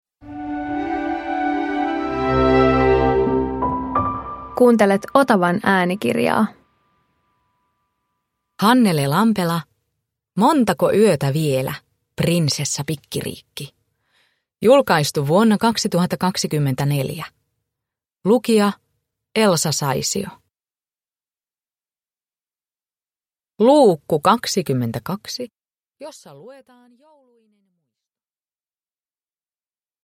Montako yötä vielä, Prinsessa Pikkiriikki 22 – Ljudbok
Uppläsare: Elsa Saisio